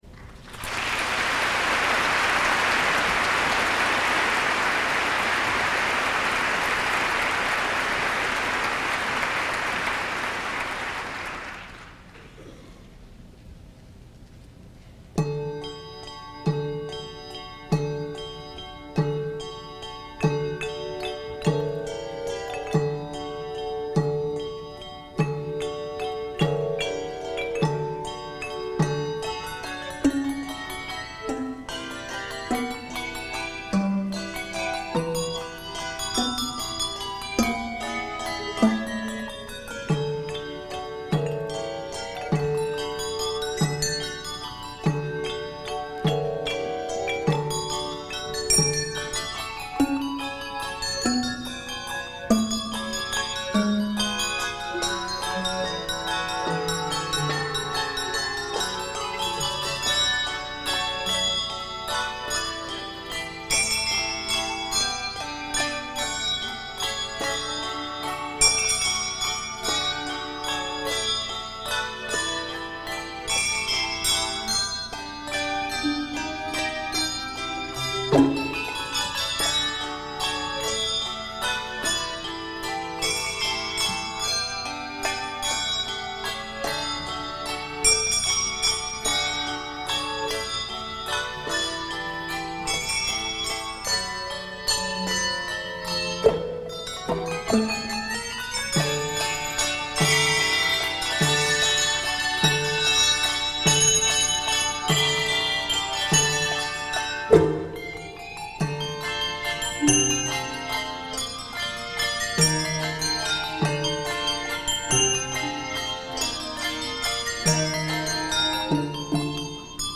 Collection: Christmas Show 1994
Collection: Featuring Bells
Location: West Lafayette, Indiana
Genre: | Type: Christmas Show |